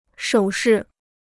手势 (shǒu shì): gesture; sign.